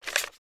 paper_putdown2.wav